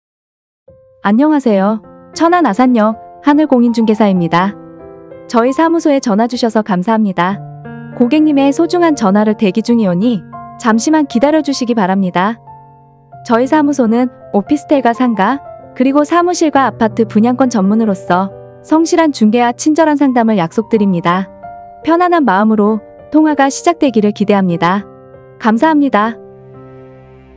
하늘공인중개사 - 통화연결음